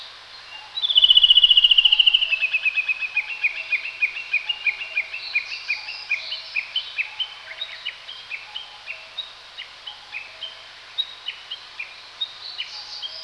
Original sound of a warbler crossing the valley as below
uguisu_valley.wav